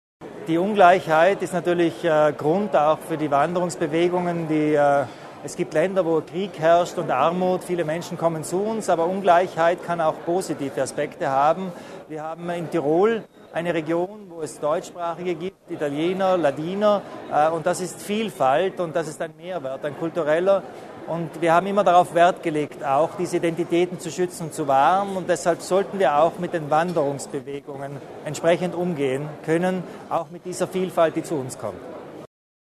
Die beiden Landeshauptmänner Arno Kompatscher und Günther Platter sowie Franz Fischler als Präsident des Europäischen Forums haben heute (23. August) den traditionellen Tiroltag in Alpbach eröffnet.